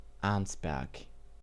Arnsberg (German pronunciation: [ˈaʁnsbɛʁk]